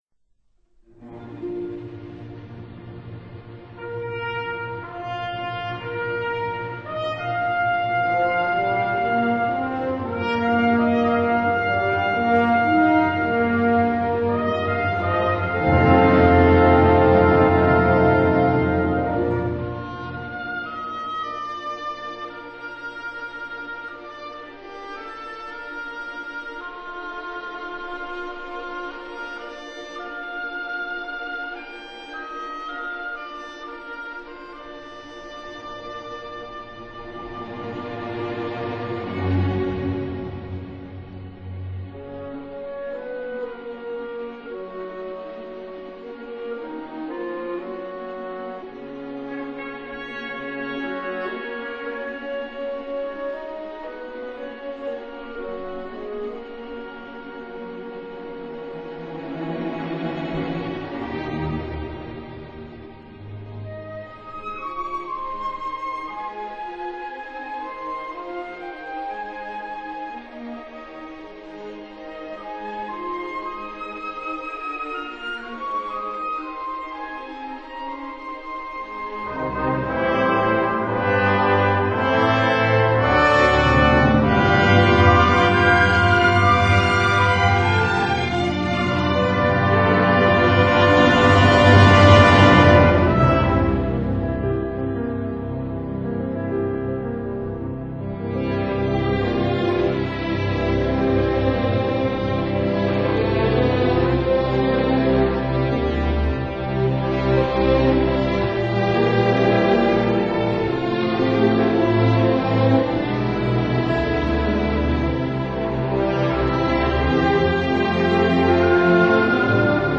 ☆层次分明的音乐，丰富的情感，是身为华夏子民的您